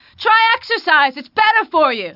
白人慢跑女青年
性别 GTAVCGender Female Icon.png女性
年龄 GTAVCAge Young Icon.png年轻